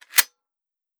fps_project_1/30-30 Lever Action Rifle - Loading 002.wav at fc29636ee627f31deb239db9fb1118c9b5ec4b9f